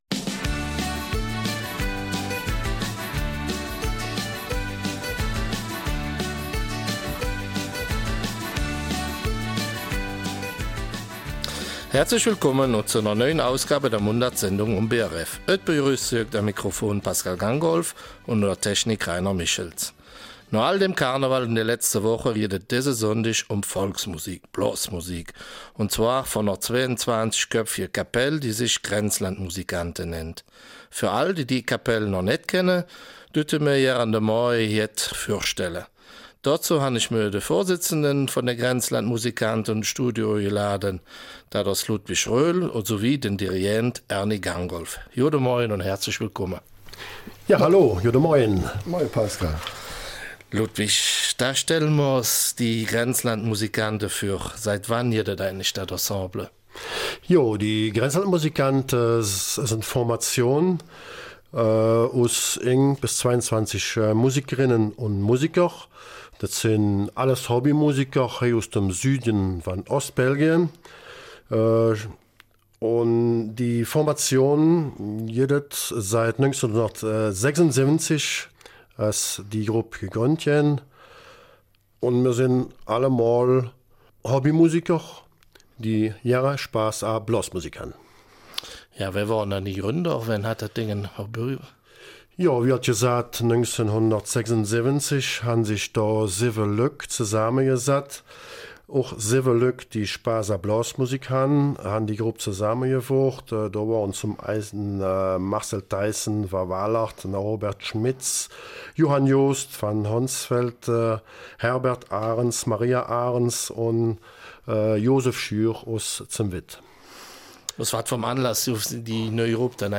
Junge Musiker stießen dazu und heute zählt die Formation 22 Musiker und Musikerinnen - alles Leute, die Spaß an moderner Blasmusik haben, aber auch noch gerne traditionelle Stücke spielen.